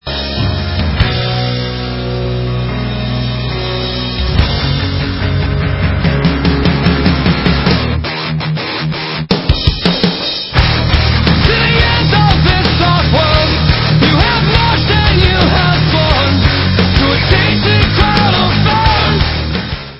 Americká punkrocková kapela